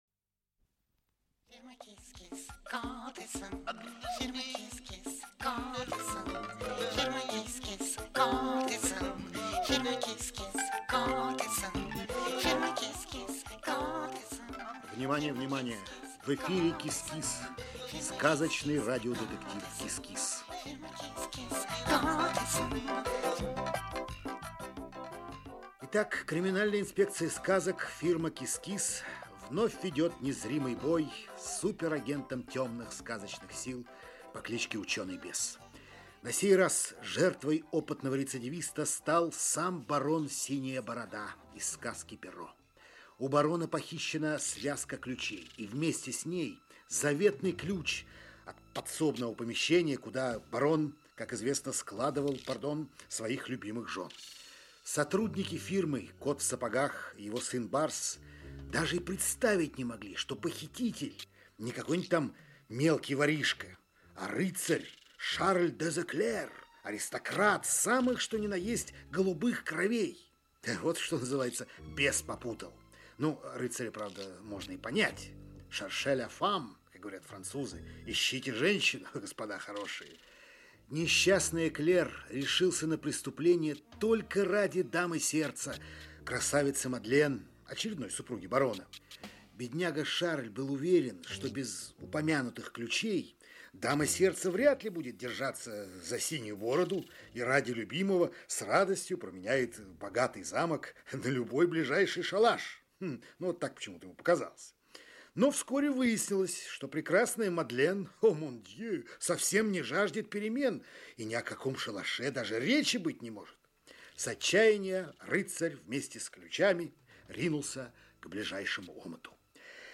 Аудиокнига КИС-КИС. Дело № 6. "Тайна Черного Замка". Часть 3 | Библиотека аудиокниг